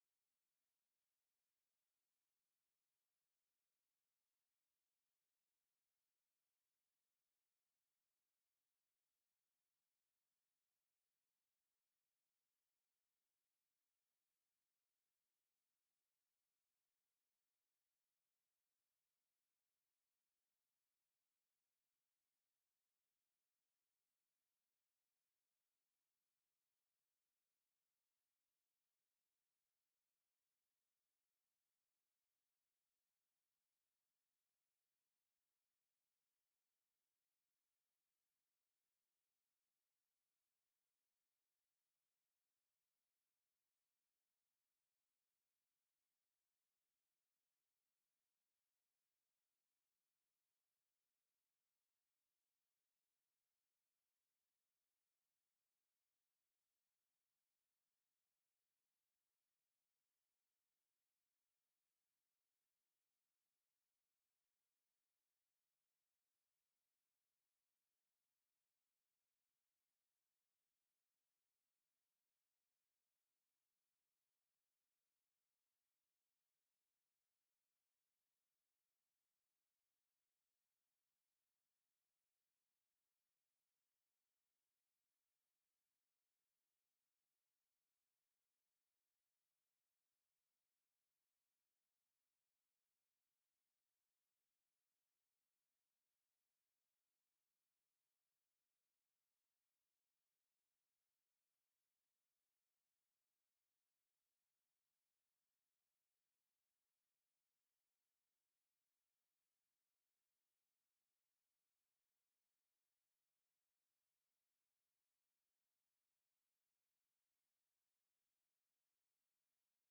Conversation with RICHARD GOODWIN and OFFICE CONVERSATION, October 27, 1964
Secret White House Tapes